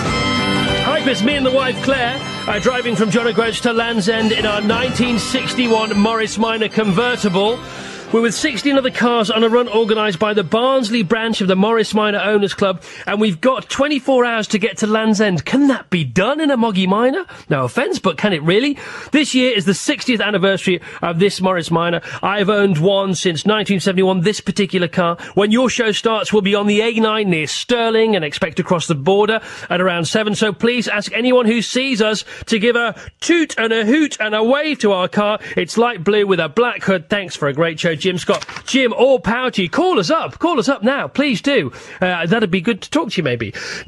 I have now uploaded an MP3 of the relevant bit of the radio programme:
JOGLE_2008_Chris_Evans_Drivetime_Radio_2.mp3